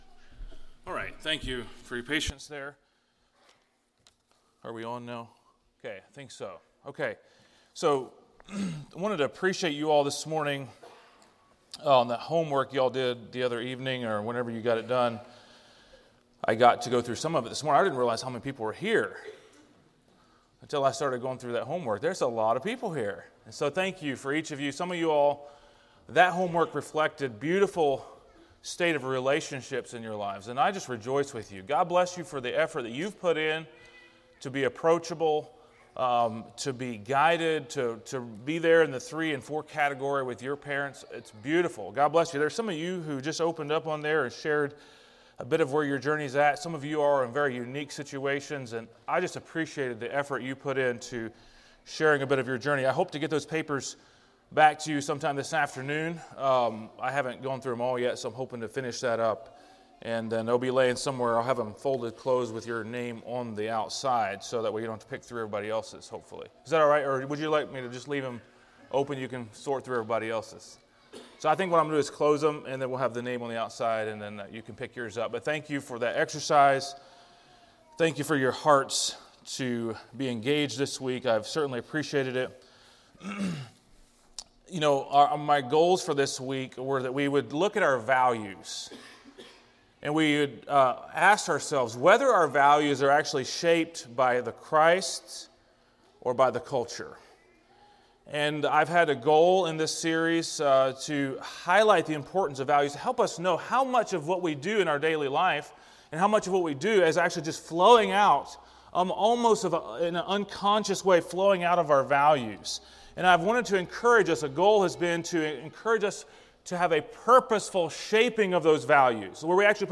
A message from the series "Bible Boot Camp 2024."